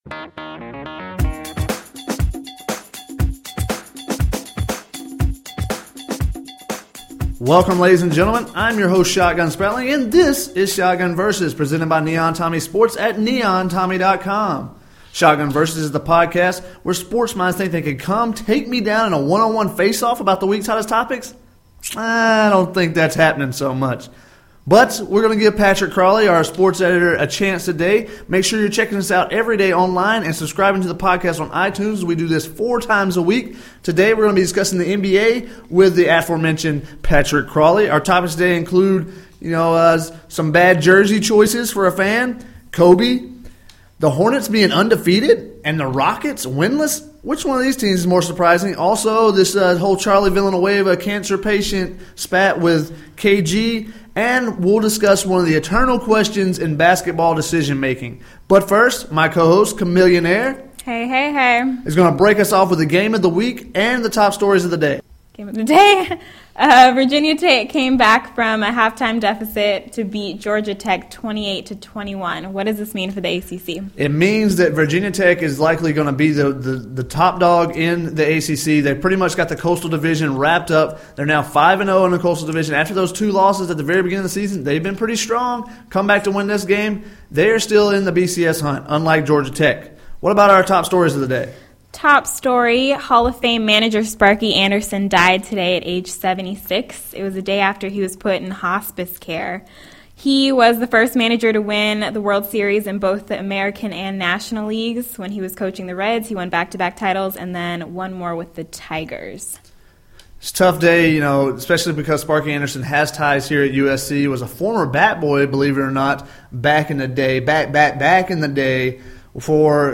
NBA Debate